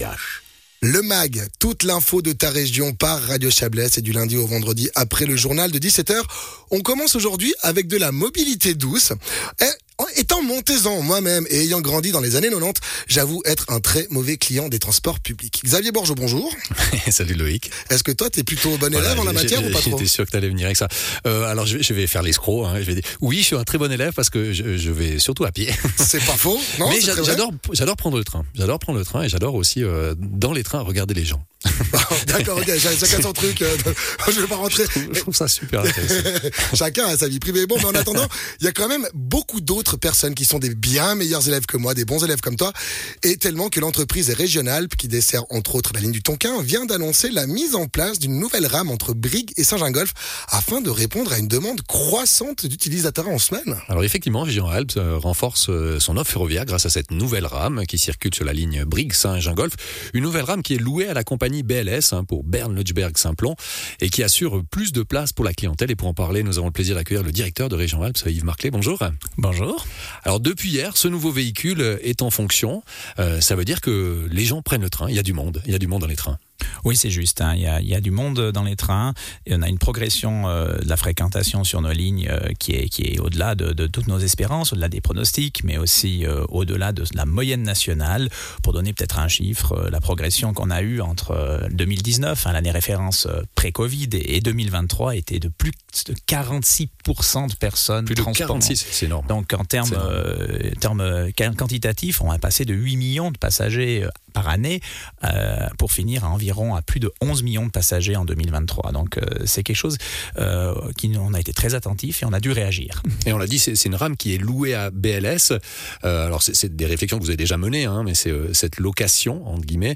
RegionAlps renforce son offre ferroviaire grâce à une nouvelle rame qui circule sur la ligne Brigue-St-Gingolph. Interview